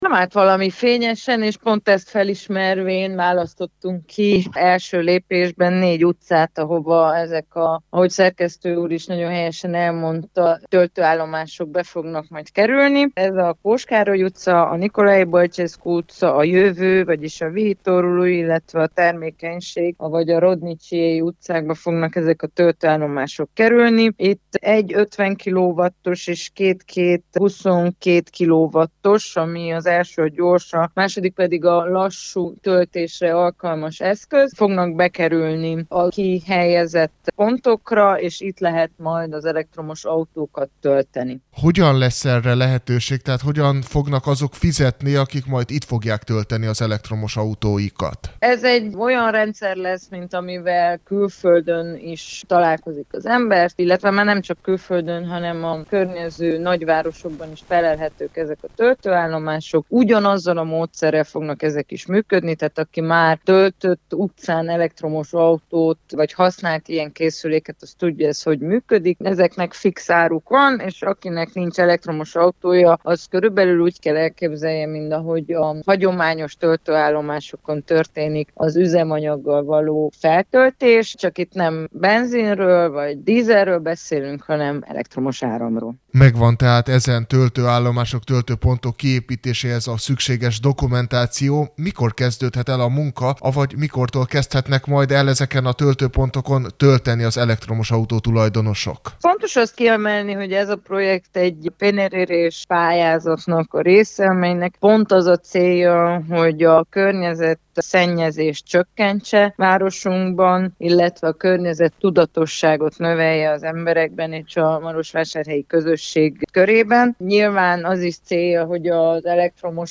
Az elektromos autó egy egyre vonzóbb opció számos ember számára, azonban Marosvásárhely nem állt valami fényesen a töltőinfrastruktúra terén, ezen javítunk most, mondta Frunda Csenge, városi tanácsos, az RMDSZ frakcióvezetője, akivel arról is beszélgettünk, hogy egyes tanácstagok nehezményezik a magyar nyelvű kommunikációt a tanács ülésein.